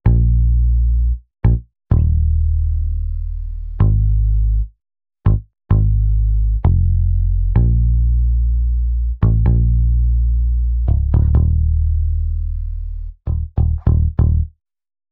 14 bass C.wav